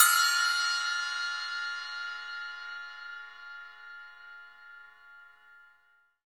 Index of /90_sSampleCDs/Roland - Rhythm Section/CYM_FX Cymbals 1/CYM_Splash menu
CYM ROCK 0AR.wav